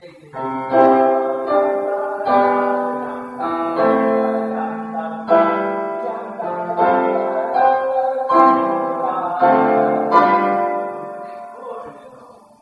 [189-,tutti]在莫扎特的时代所有的钢琴家都会接着往下弹和声[示范通奏低音]：